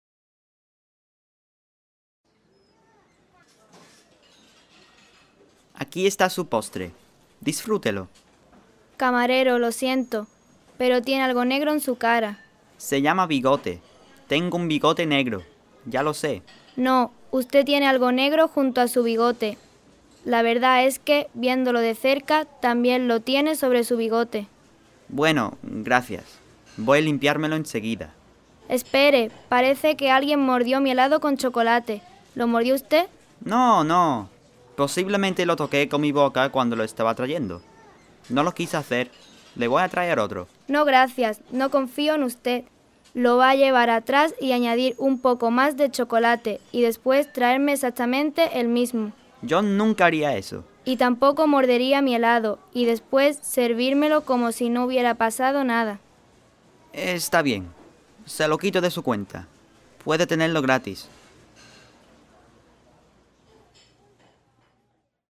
Whimsical Dialogues for Upper Level Classes
The Spanish enactment (mp3) can be played while the students read along.